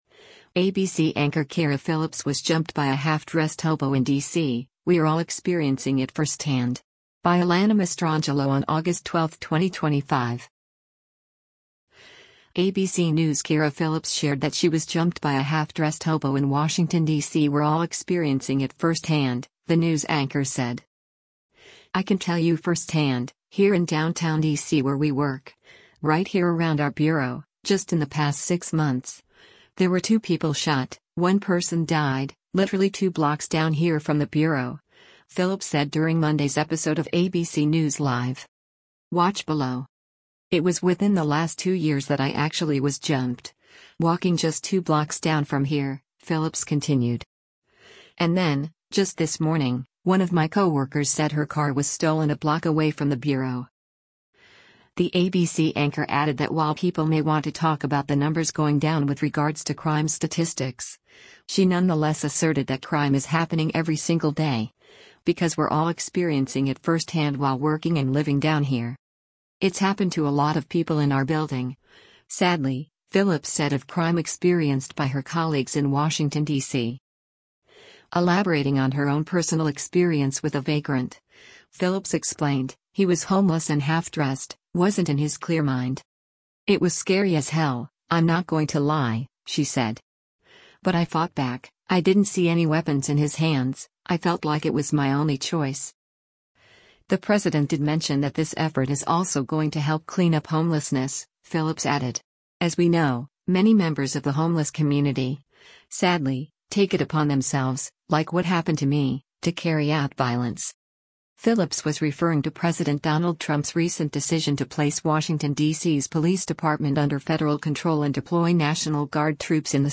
“I can tell you firsthand, here in downtown D.C. where we work, right here around our bureau, just in the past six months, there were two people shot, one person died, literally two blocks down here from the bureau,” Phillips said during Monday’s episode of ABC News Live.